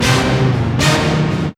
3504R BIGHIT.wav